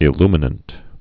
(ĭ-lmə-nənt)